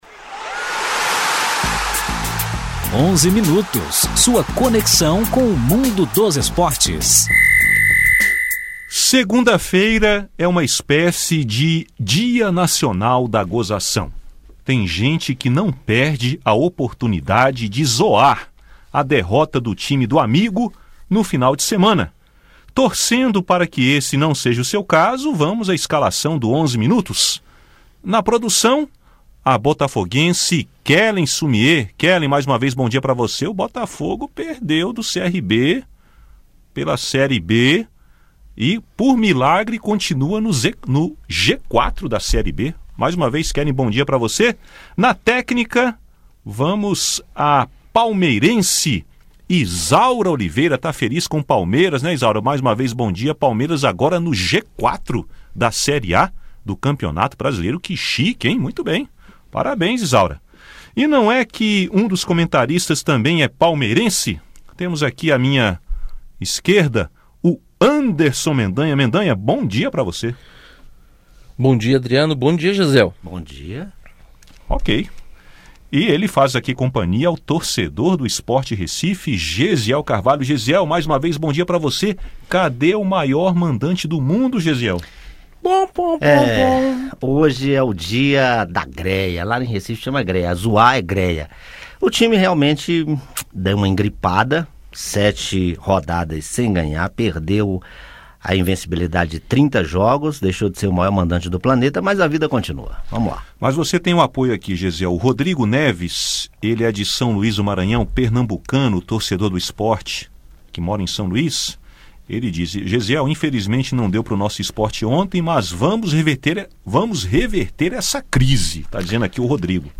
Supremo Tribunal Federal – STF nega pedido da CBF de não enviar prestação de contas da entidade para a CPI do Futebol do Senado, e a derrota do Botafogo e a subida do Palmeiras para o G4 da série A do Campeonato Brasileiro de futebol são alguns assuntos dos comentaristas do “Onze Minutos” do programa Conexão Senado.